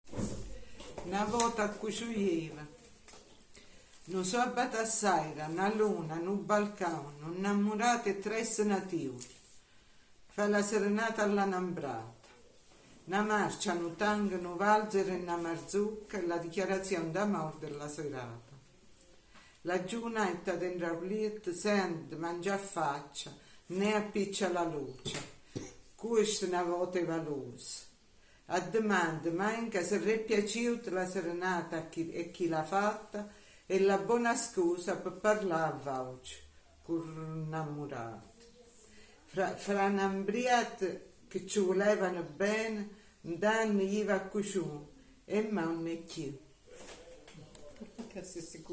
Il gruppo che recita le poesie in dialetto e che canta le due canzoni è composto da